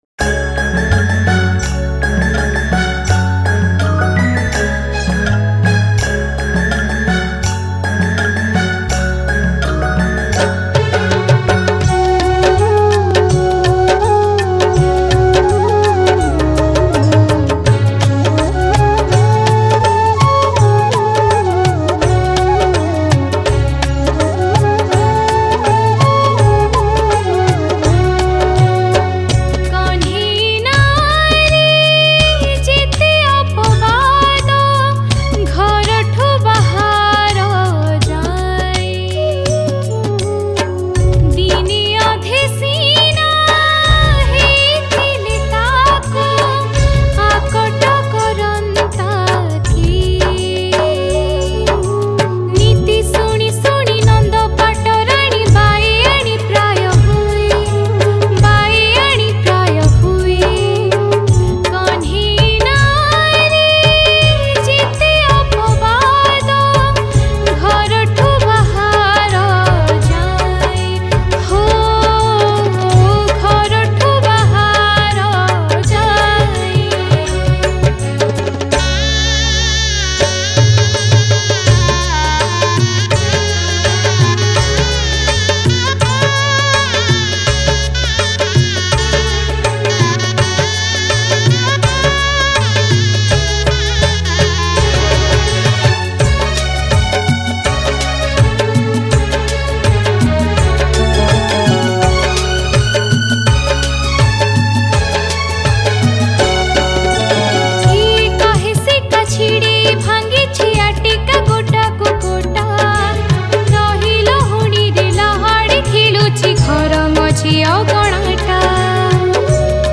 Category: Odia Bhakti Hits Songs